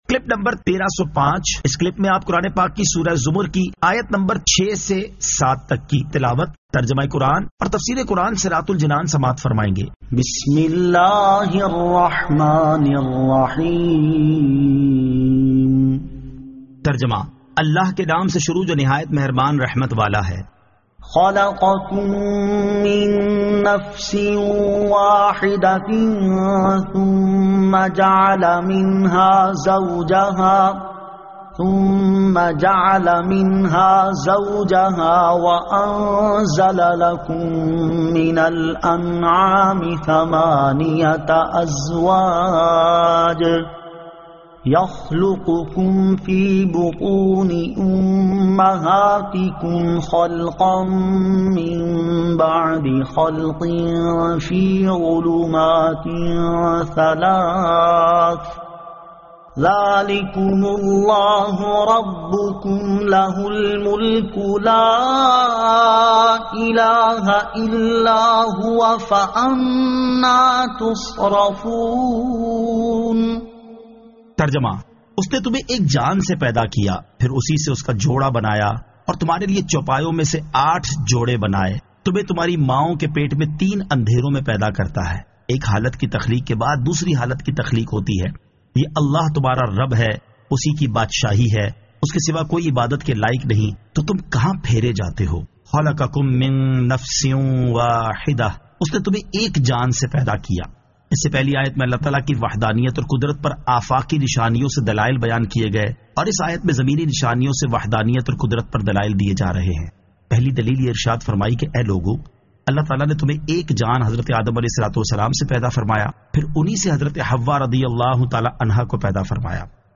Surah Az-Zamar 06 To 07 Tilawat , Tarjama , Tafseer